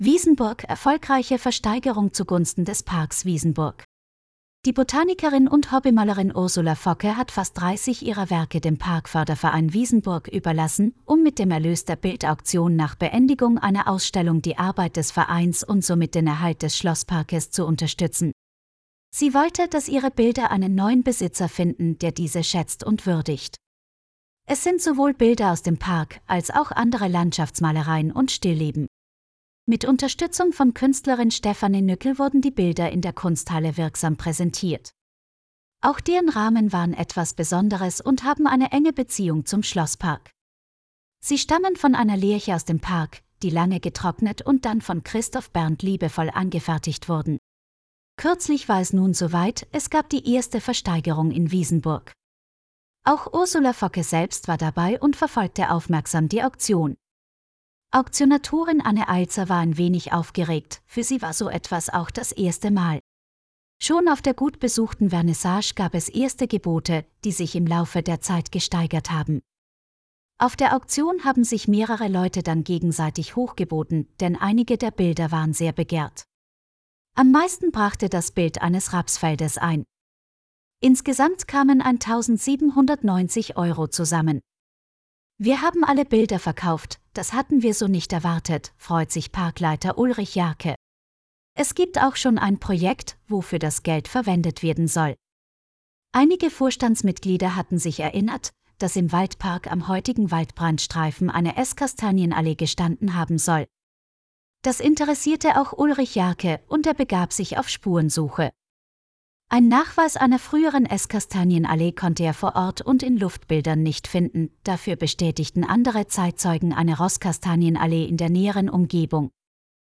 Diesen Text kannst du dir auch anhören. Dazu bekamen wir Unterstützung von einer Künstlichen Intelligenzen.